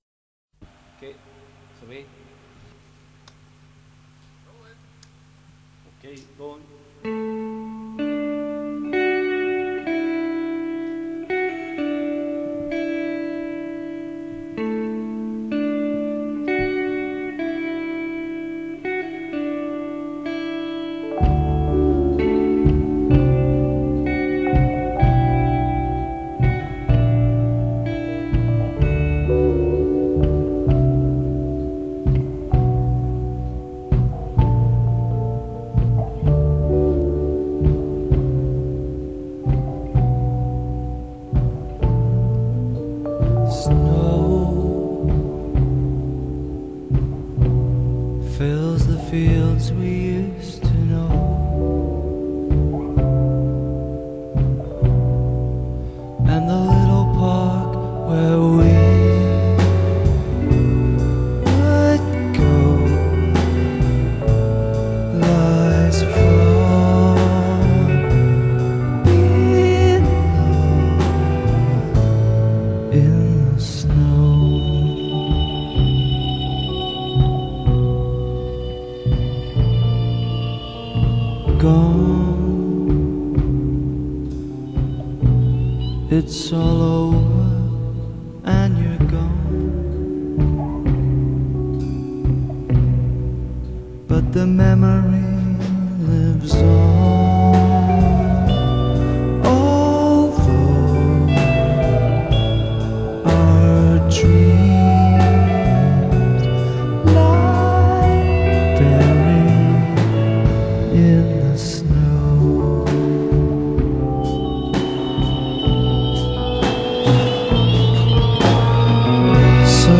It has that eerie and wooly, comforting and cocooning sound.